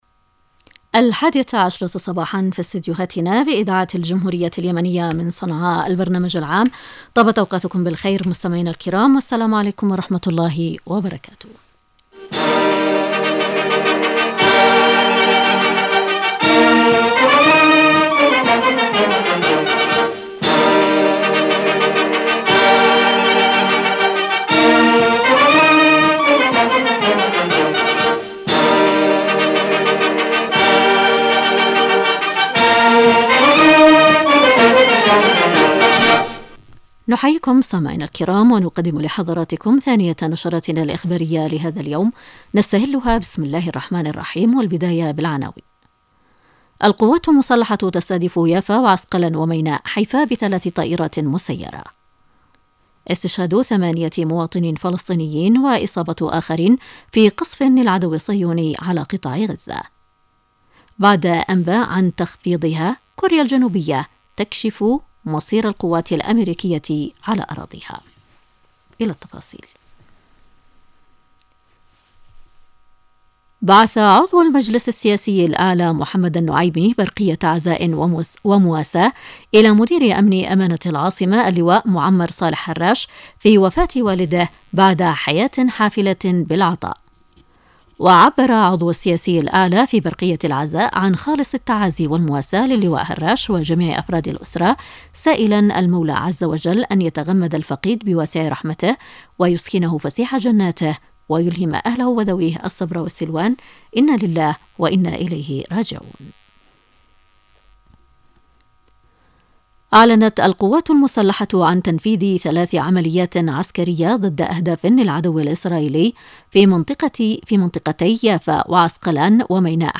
نشرة الحادية عشرة